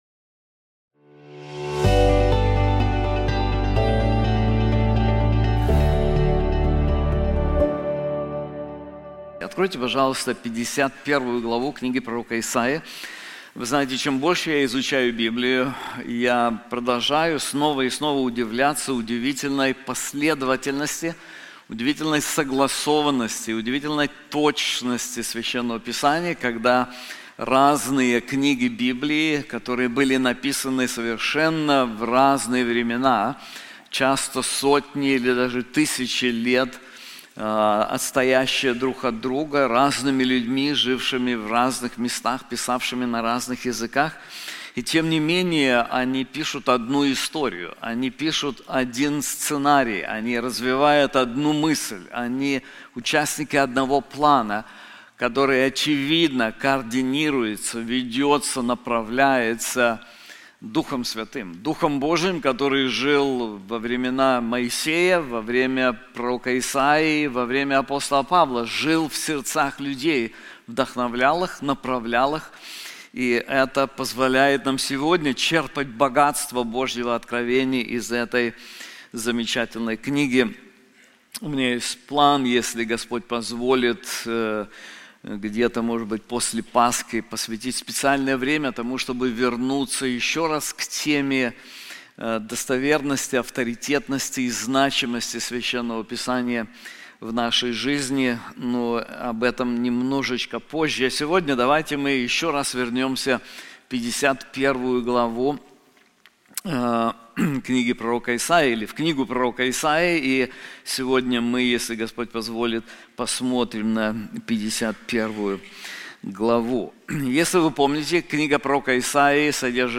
This sermon is also available in English:An Effective Hope • Isaiah 51:1-23